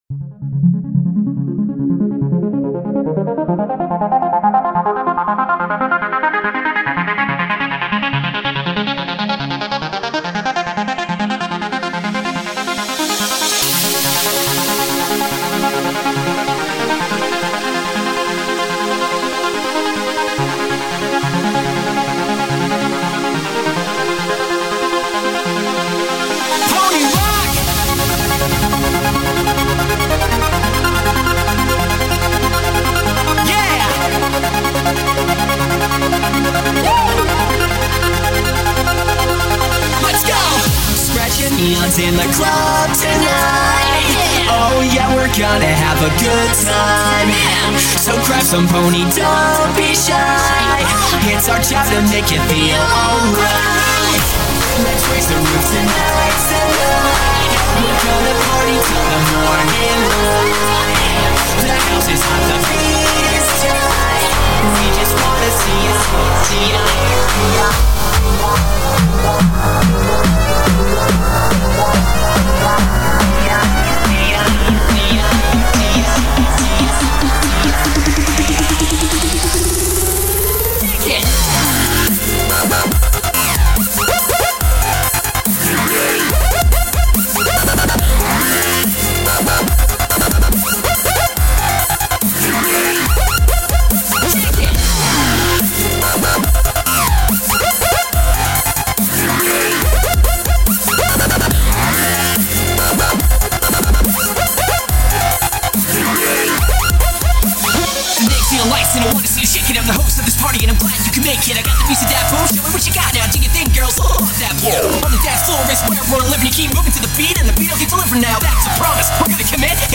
genre:dubstep
genre:remix